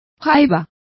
Complete with pronunciation of the translation of crab.